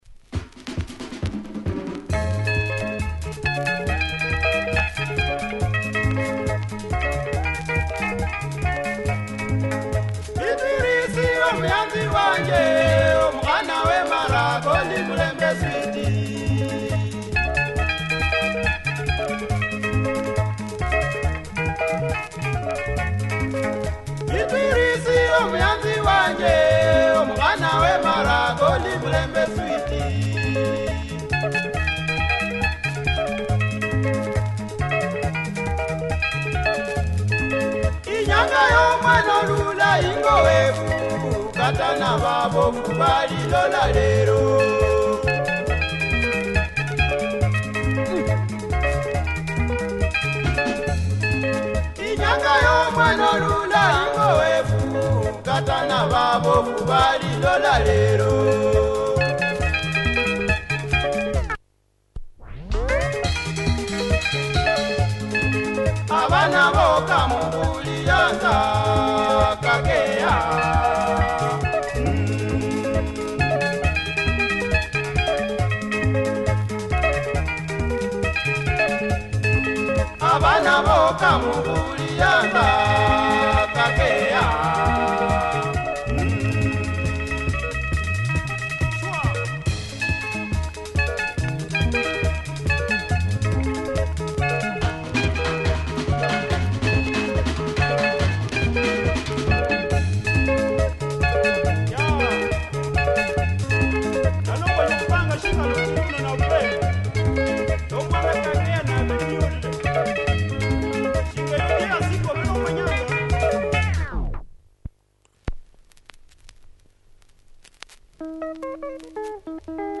Nice luhya benga, clean as clean can be.